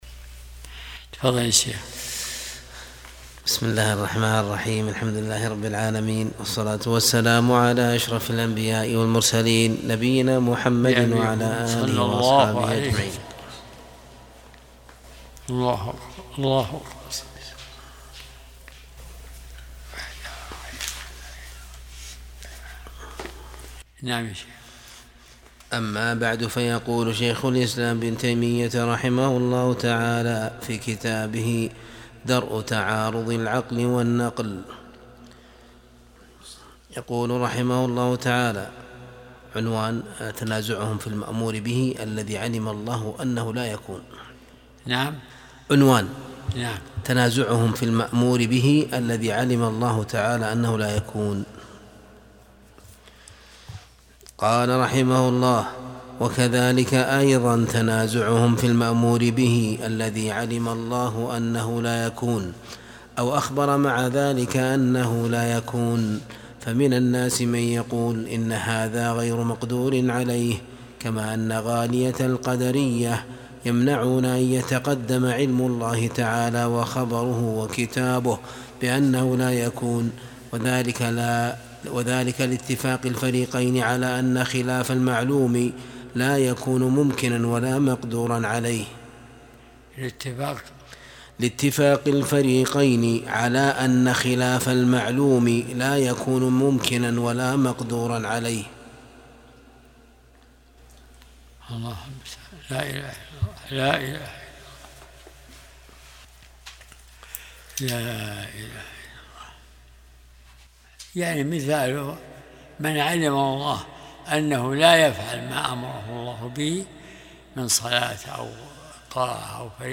درس الأحد 71